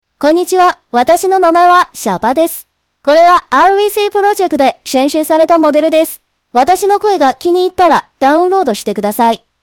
吉伊卡哇（chiikawa）小八 RVC模型